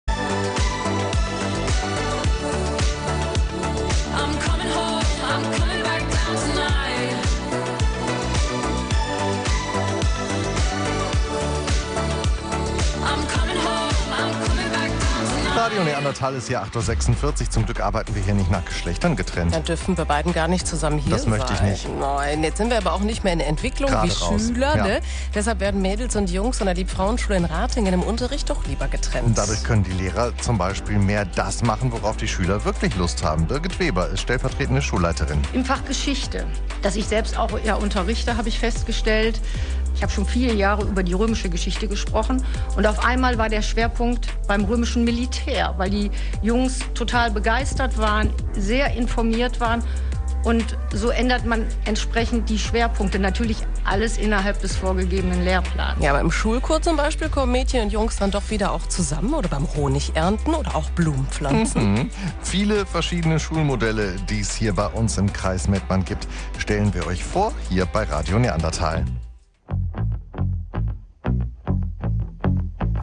LFS Ratingen live auf Radio Neandertal
1.Statement hier